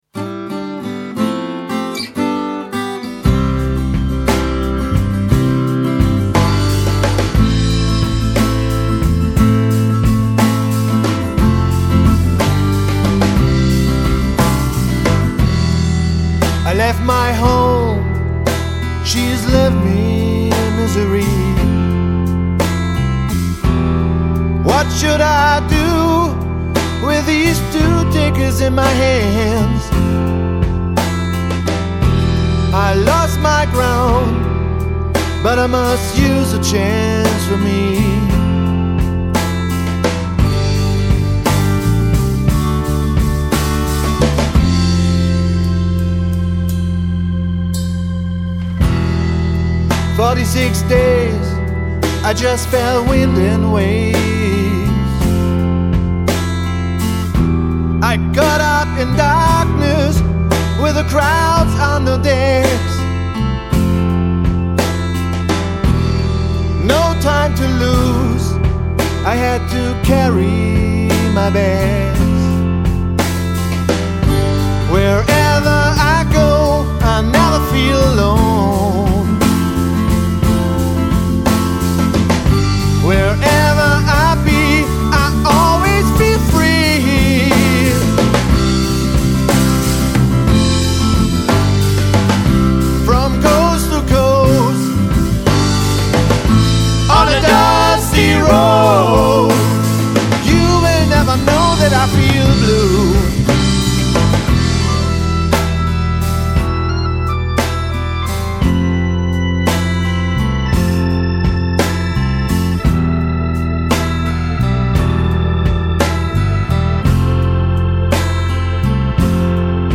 Rauchig und mild, versöhnlich und verträumt.
eingebettet in 70er Jahre Orgeltöne,
aufgewirbelt vom Klang einer Mundharmonika.
mit vollem Bass und tragendem Schlagzeug
entsteht eine mitreißende Spielfreude
und packende Dynamik.